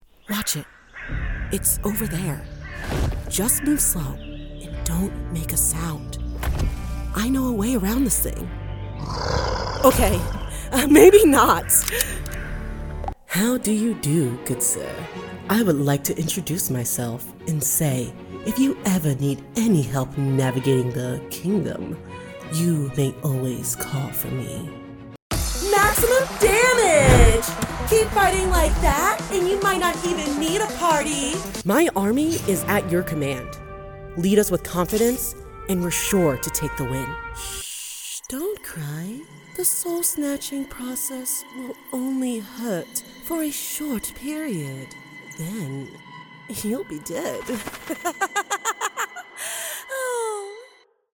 Video Game Demo